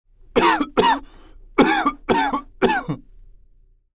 Звуки першения в горле
На этой странице собраны различные звуки першения в горле – от легкого покашливания до навязчивого дискомфорта.